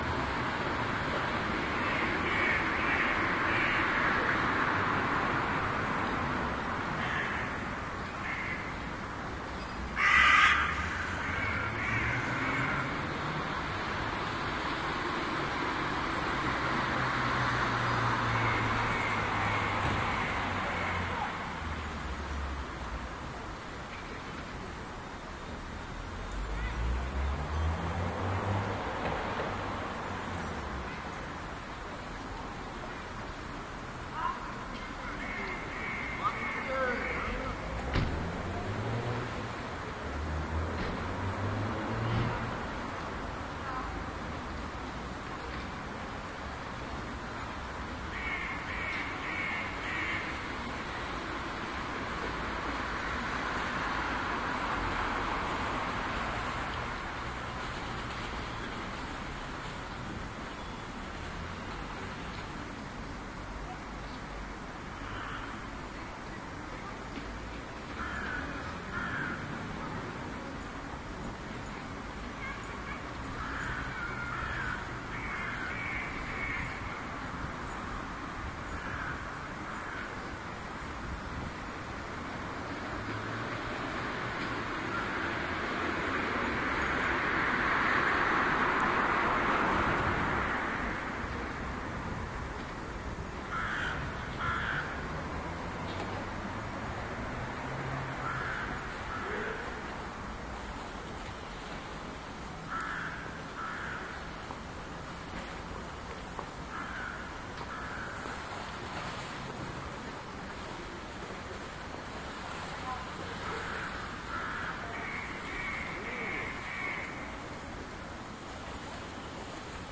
铁路 " 汉诺威站
描述：没有什么比在等待火车的时候，做一些老式的现场录音更好的事情了汉诺威站，OKM双耳话筒，A3适配器插入R09 HR录音机。
Tag: 火车站 双耳 现场记录 汉诺威 铁路 车站 列车 trainstation